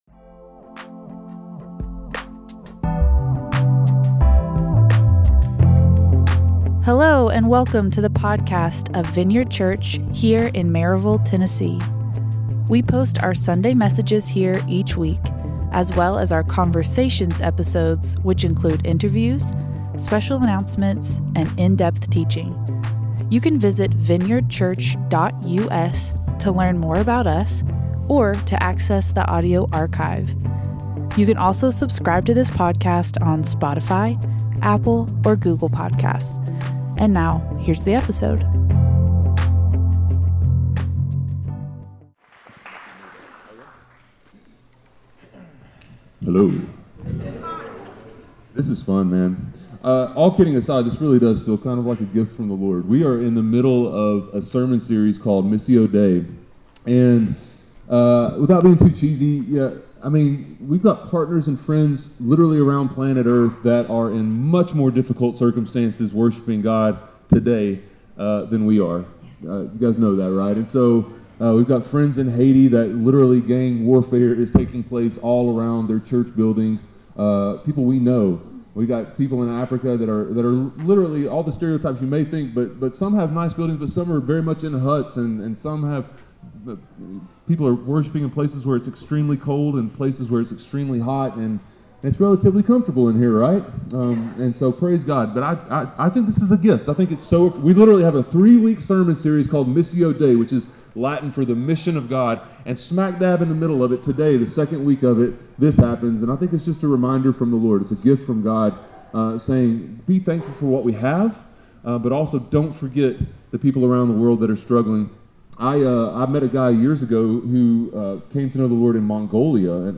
A message from the series "Missio Dei."